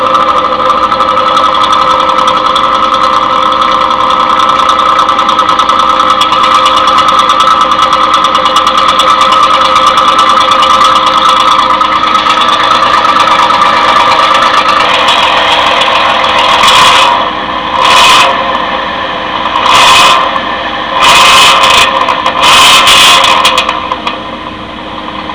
Click here to hear my car.
car.wav